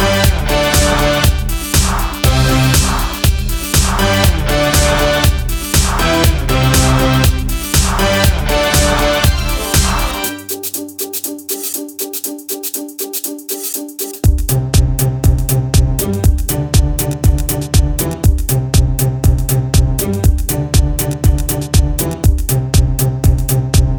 With Rapper Pop (2000s) 3:54 Buy £1.50